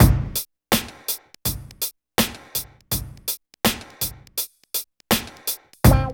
134 DRM LP-L.wav